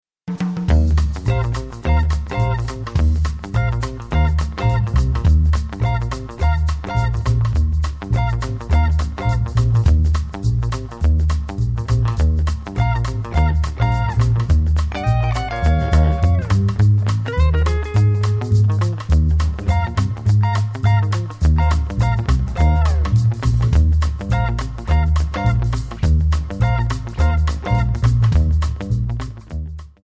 Backing track files: 1960s (842)
Buy With Backing Vocals.